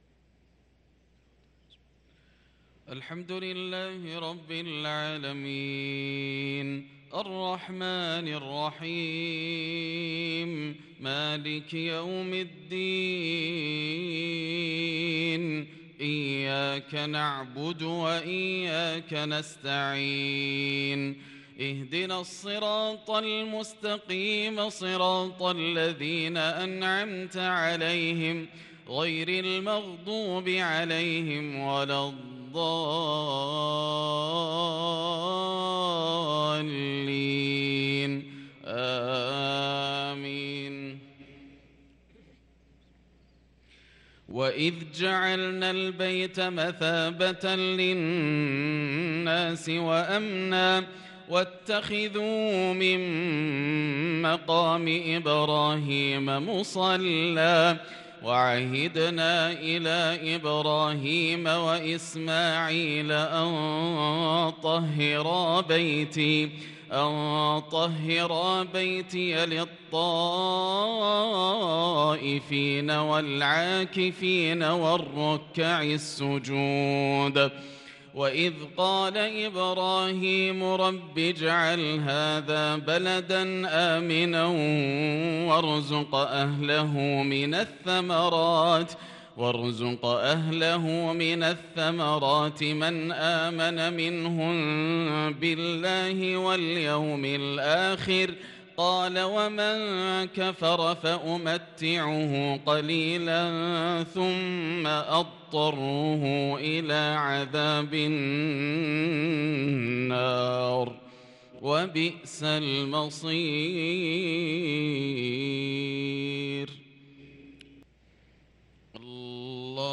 صلاة المغرب للقارئ ياسر الدوسري 8 ذو الحجة 1443 هـ
تِلَاوَات الْحَرَمَيْن .